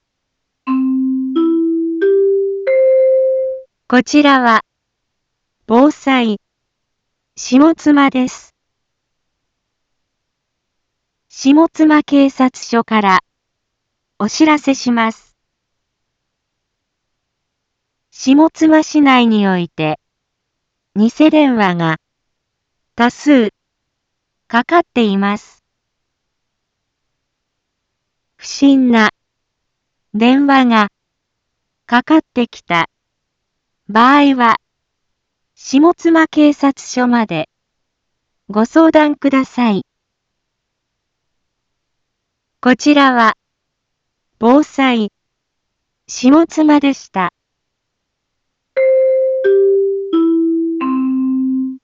一般放送情報
BO-SAI navi Back Home 一般放送情報 音声放送 再生 一般放送情報 登録日時：2023-03-15 15:30:56 タイトル：ニセ電話詐欺にご注意を インフォメーション：こちらは、防災、下妻です。